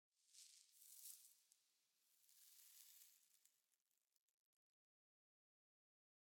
1.21.5 / assets / minecraft / sounds / block / sand / sand16.ogg
sand16.ogg